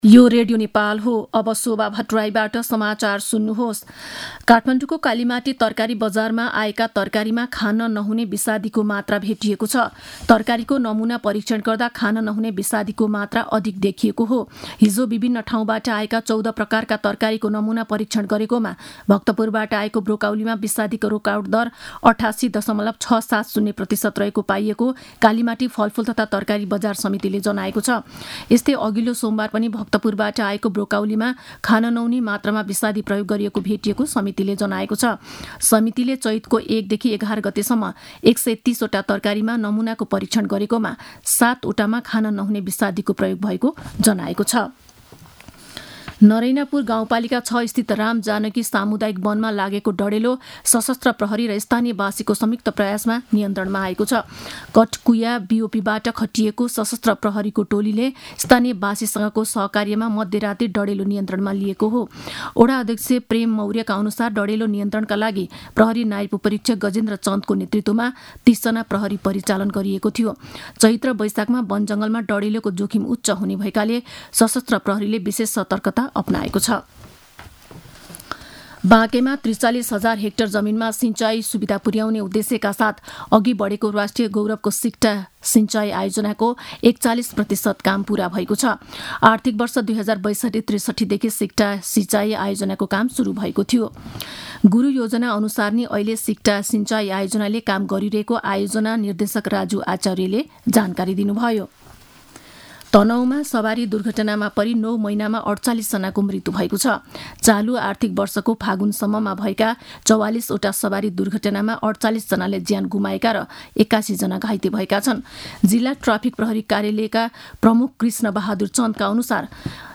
मध्यान्ह १२ बजेको नेपाली समाचार : १२ चैत , २०८१